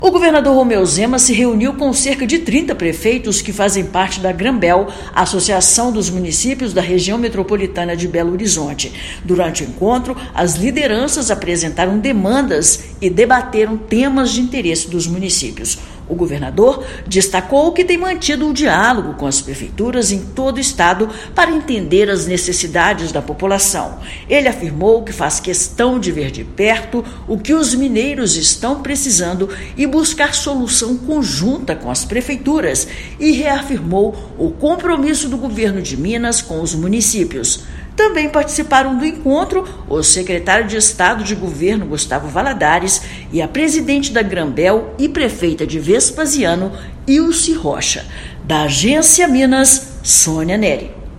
Lideranças políticas apresentaram demandas dos municípios, fortalecendo o diálogo com o Governo de Minas. Ouça matéria de rádio.